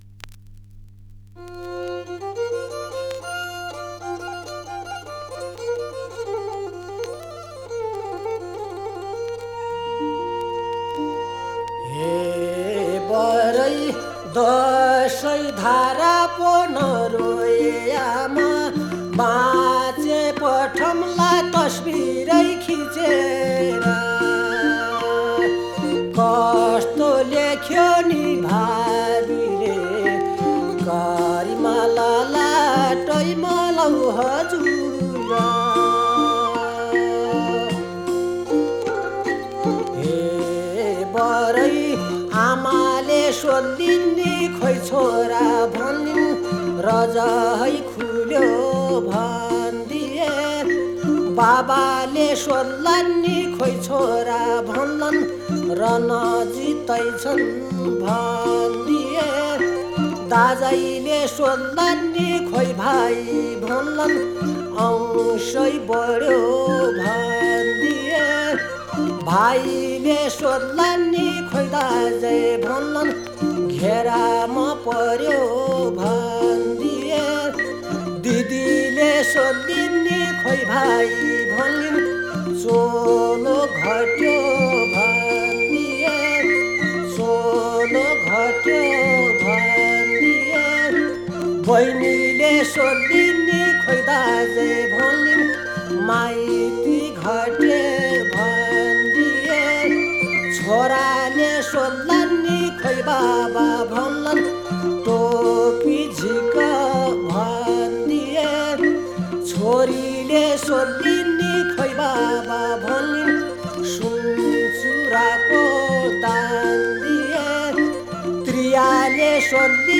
disque microsillon 45 tours 17 cm 4 titres